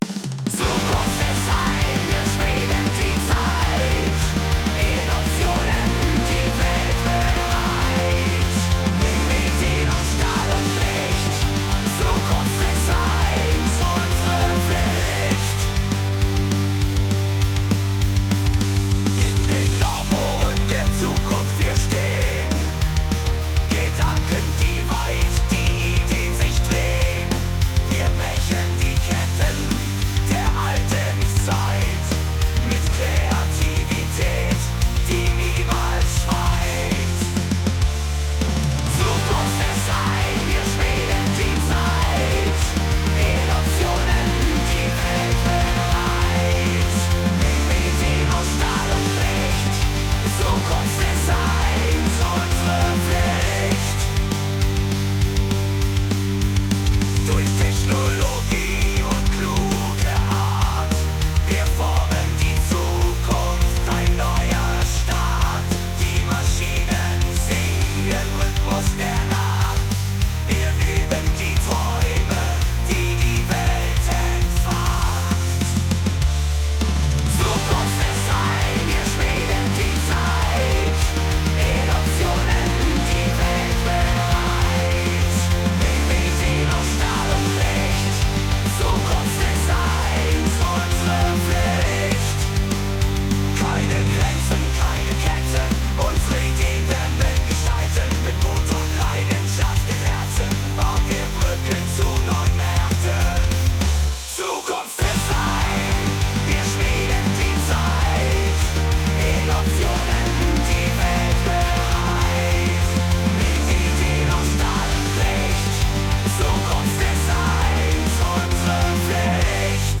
Metall_ZukunftsDesign_Song.mp3